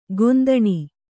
♪ gondaṇi